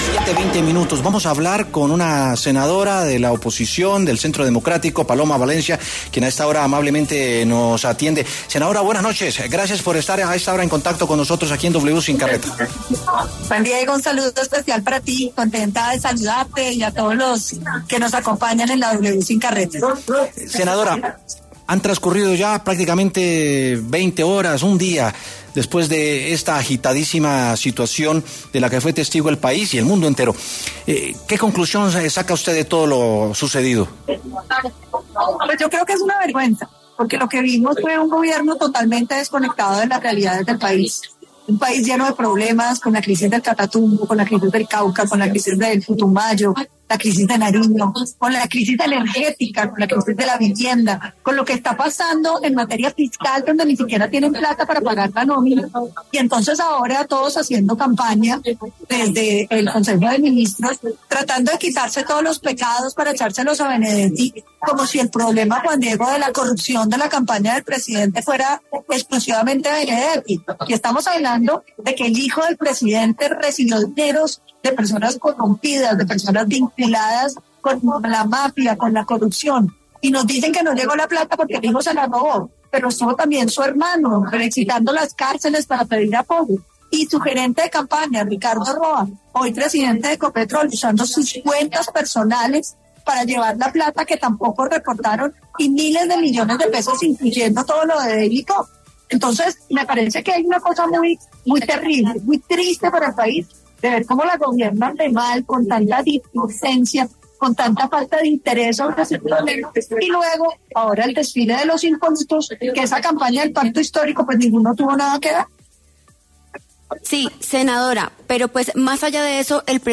Ahora, la reacción fue por parte de la Senadora del Centro Democrático Paloma Valencia, quien en W Sin Carreta dio su opinión.